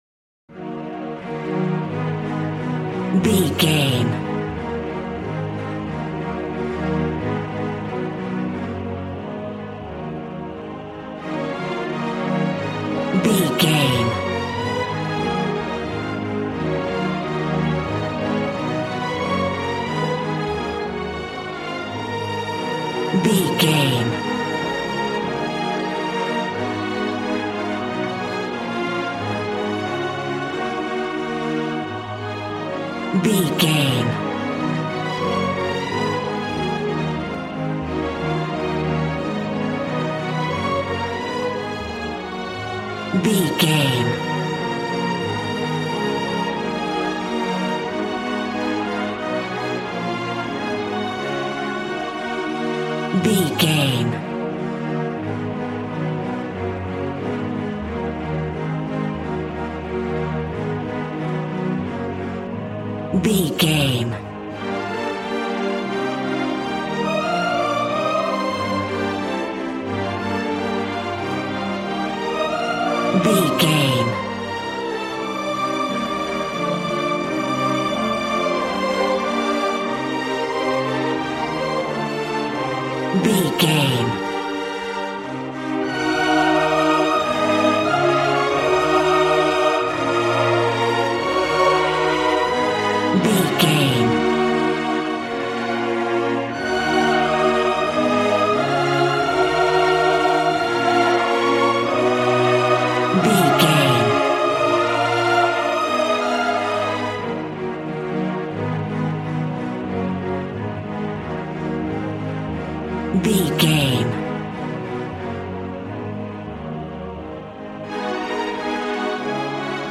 Aeolian/Minor
E♭
regal
cello
violin
brass